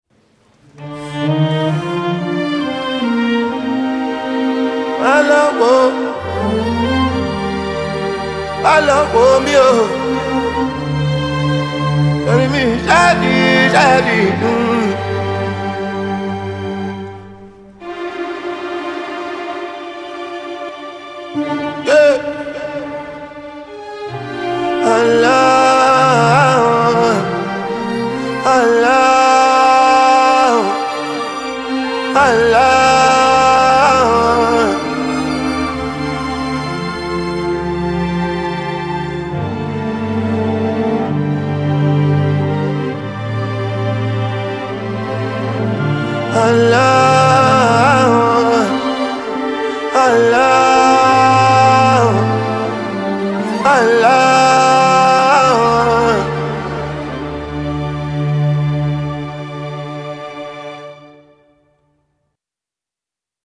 Genre : Soul, Funk, R&B